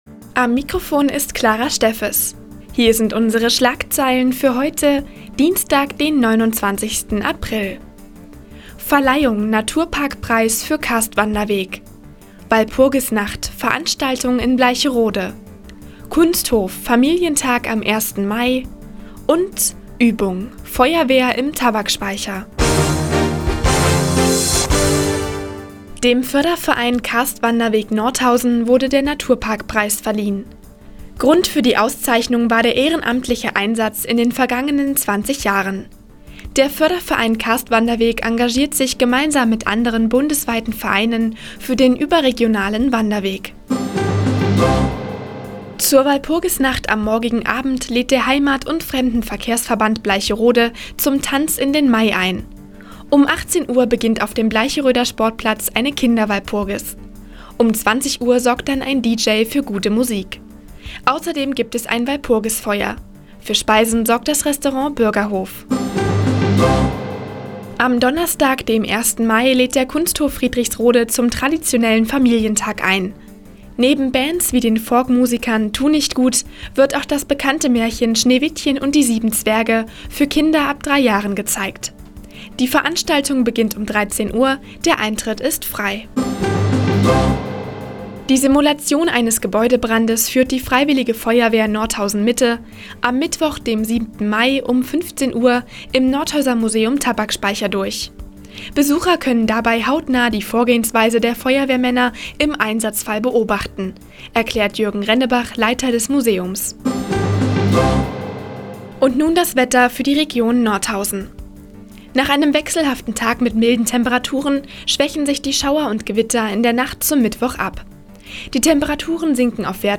Di, 15:00 Uhr 29.04.2014 „Der Tag auf die Ohren“ OKN (Foto: OKN) Seit Jahren kooperieren die Nordthüringer Online-Zeitungen und der Offene Kanal Nordhausen. Die tägliche Nachrichtensendung des OKN ist jetzt hier zu hören.